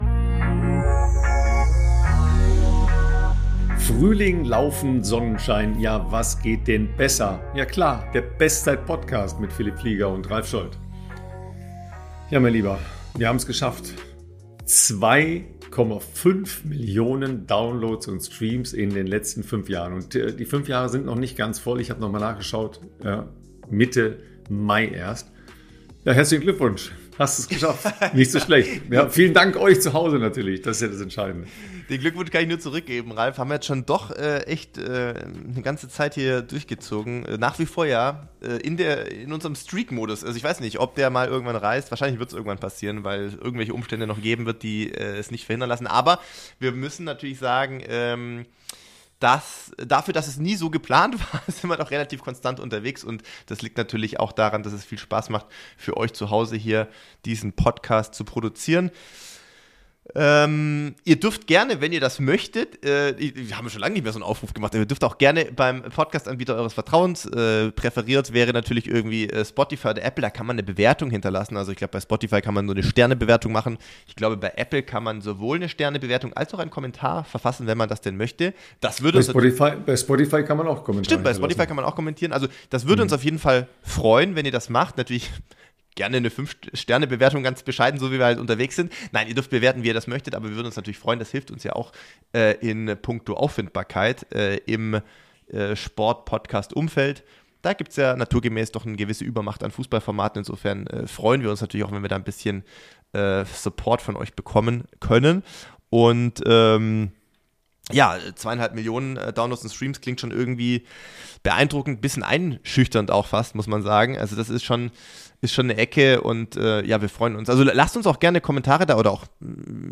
Wenn sich der Marathonprofi und der Journalist unterhalten geht es also natürlich um’s Laufen und das aktuelle Geschehen in der Ausdauersportwelt. Es geht aber auch um Behind-the-Scenes-Einblicke in ihr tägliches Leben für den Sport, ihre unterschiedlichen Erlebniswelten und die damit verbundenen Blickwinkel